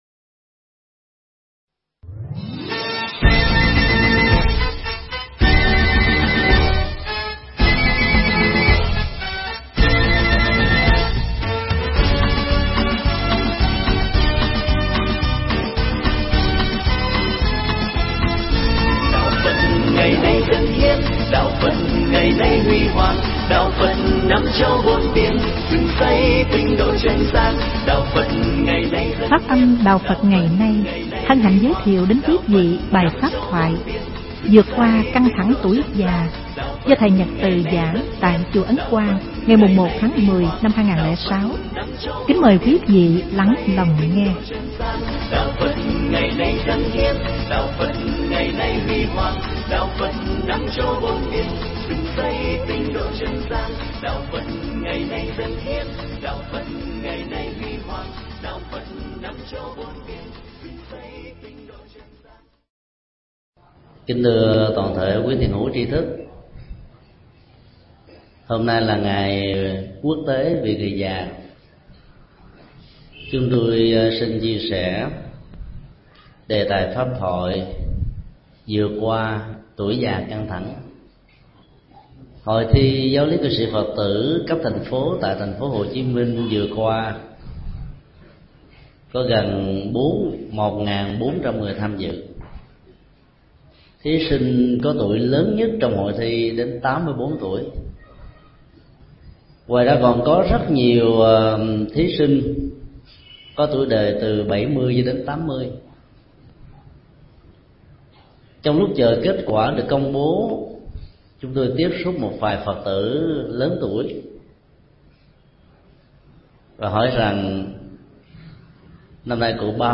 Nghe mp3 thuyết pháp Vượt qua tuổi già căng thẳng do thượng toạ Thích Nhật Từ giảng tại Chùa Ấn Quang, ngày 1 tháng 10 năm 2006.